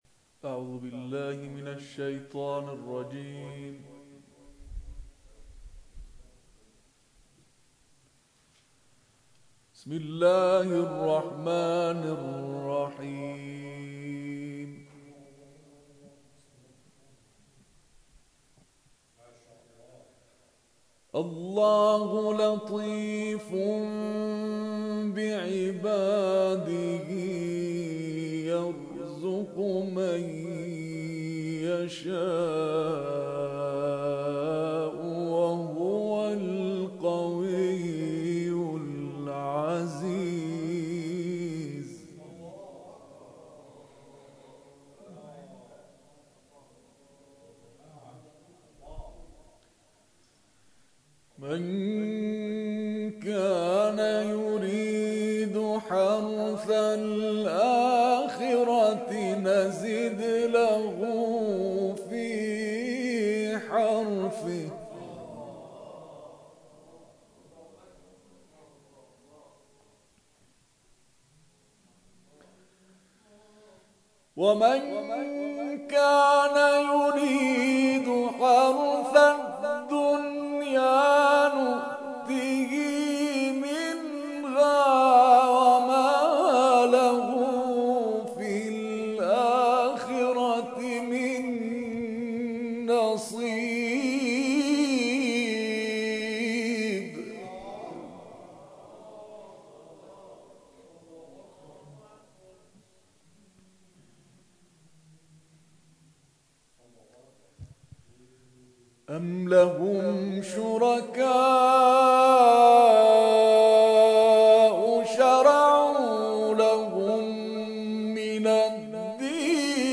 گروه جلسات و محافل
قاری بین المللی